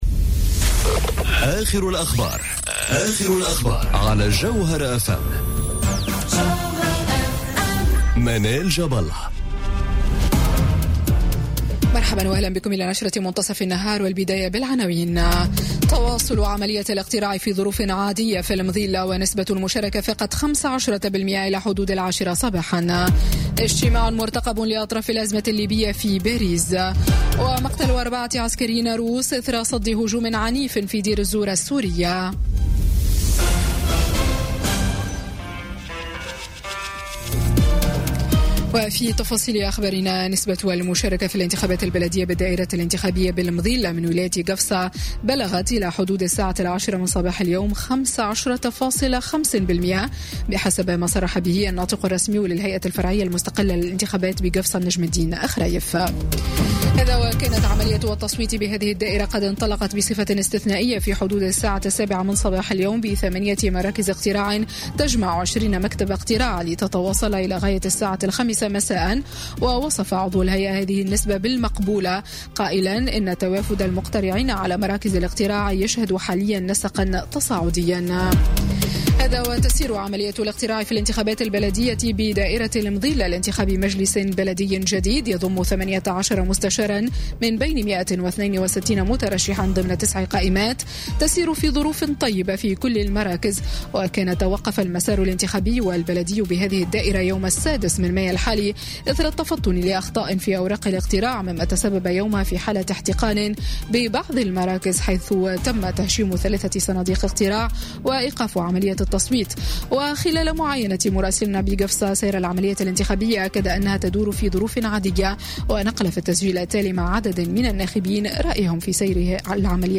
نشرة أخبار منتصف النهار ليوم الأحد 27 ماي 2018